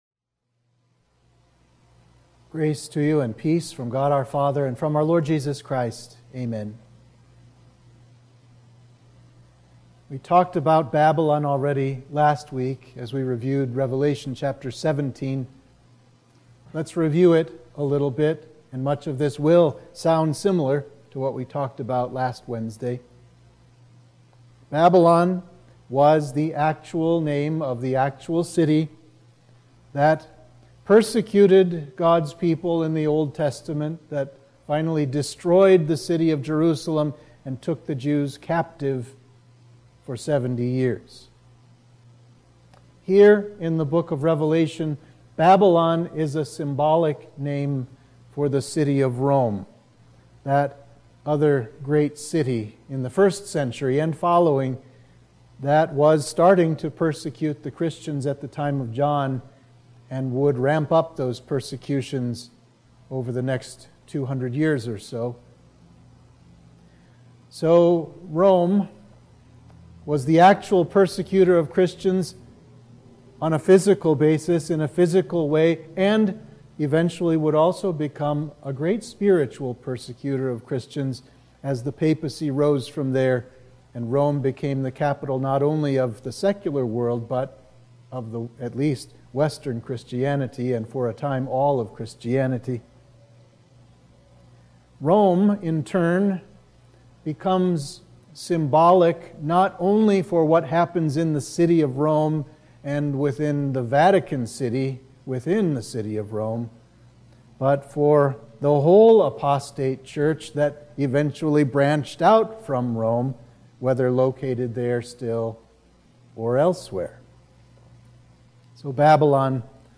Sermon for Midweek of Trinity 14